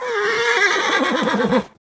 taunt1.wav